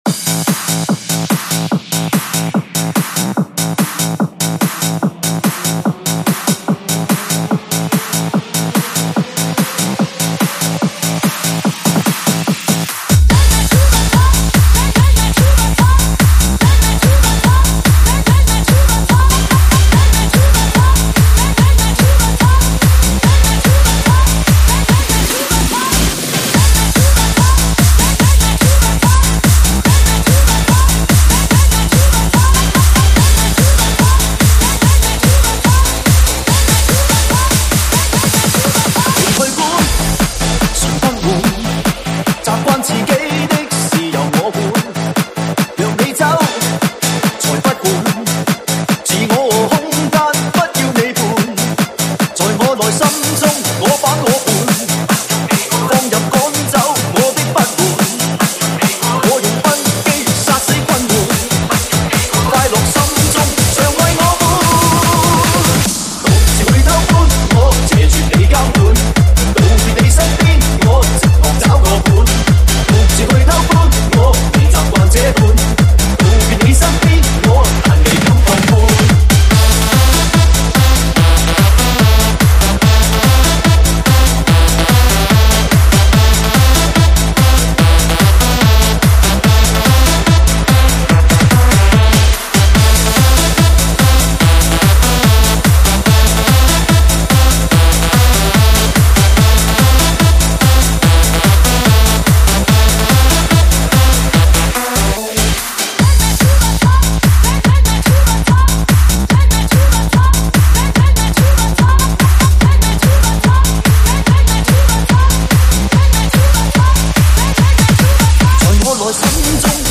试听文件为低音质，下载后为无水印高音质文件 M币 10 超级会员 M币 5 购买下载 您当前未登录！